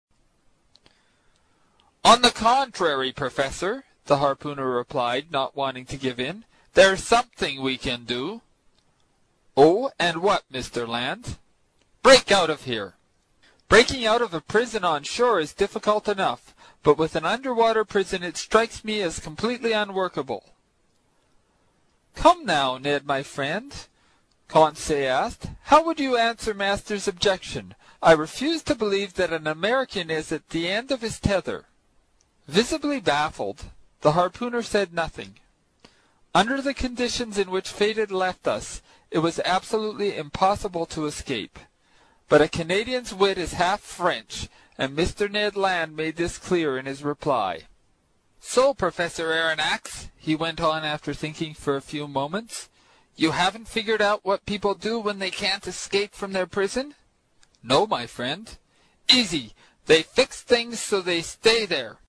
在线英语听力室英语听书《海底两万里》第124期 第9章 尼德·兰的愤怒(9)的听力文件下载,《海底两万里》中英双语有声读物附MP3下载